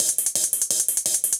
UHH_ElectroHatD_170-04.wav